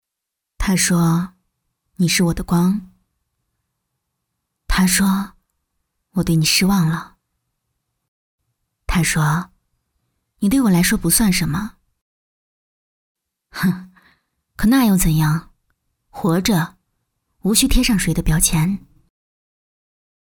女93专题广告 v93
女93-他说.mp3